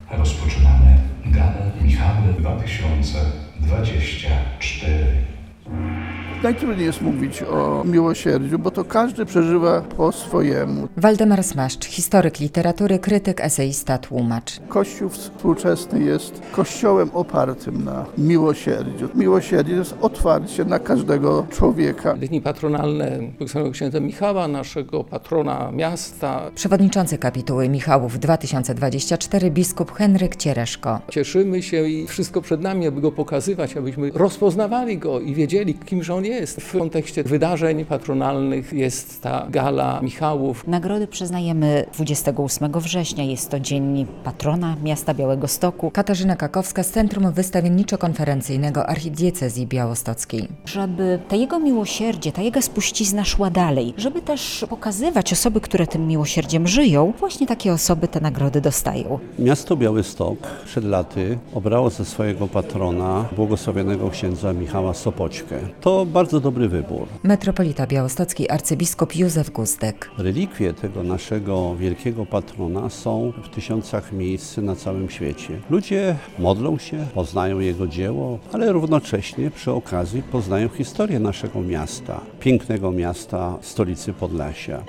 Wręczenie "Michałów" z okazji Dni Patronalnych